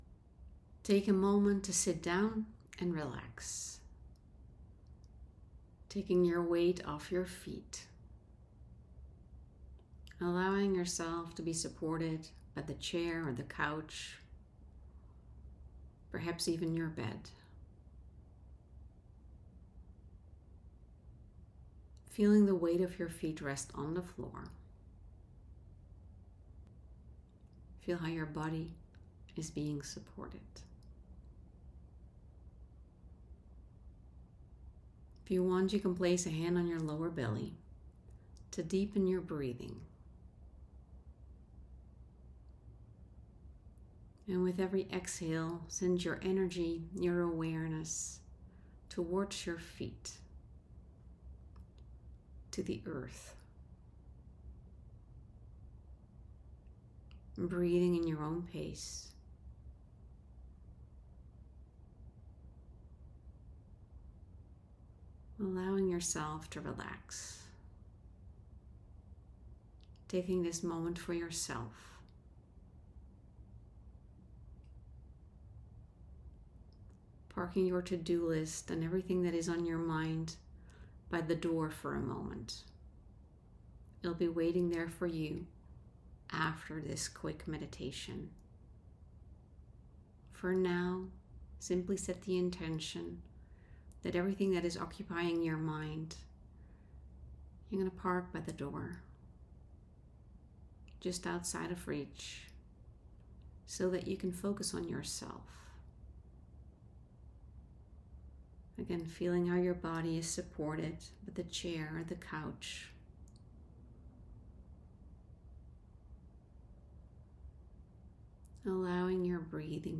I recorded a guided visualization for you—a mini meditation to give you a short break and help you reset.